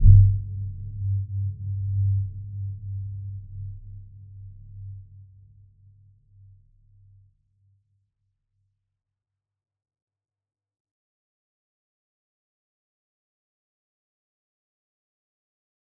Dark-Soft-Impact-G2-f.wav